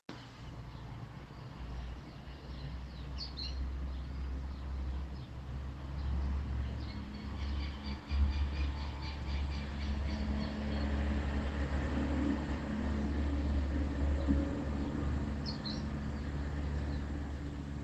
Pijuí Frente Gris (Synallaxis frontalis)
Oído claramente varias vocalizaciones, con tiempo justo para grabarlo con el celu.
Nombre en inglés: Sooty-fronted Spinetail
Localidad o área protegida: Villa Rosa
Certeza: Vocalización Grabada